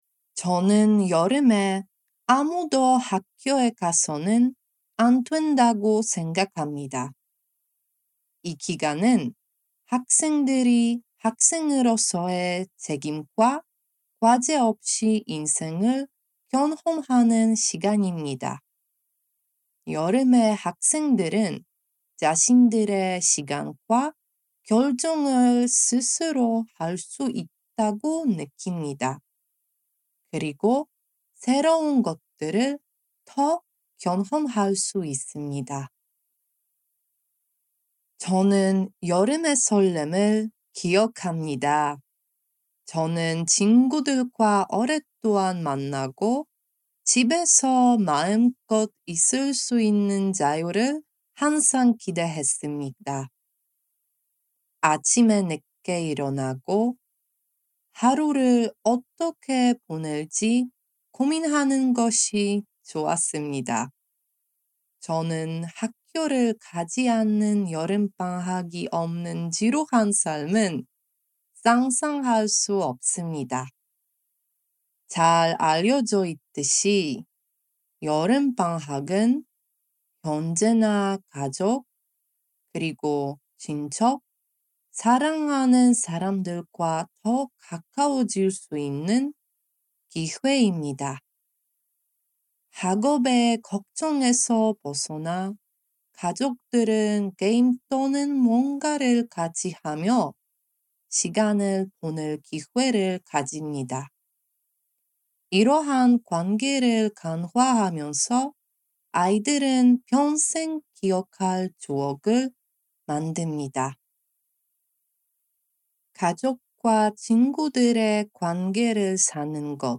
[Note: In the transcript below, ellipses indicate that the speaker paused.]